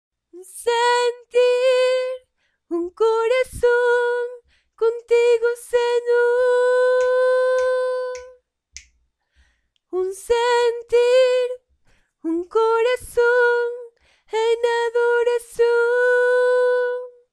2a Voz Precoro Mujer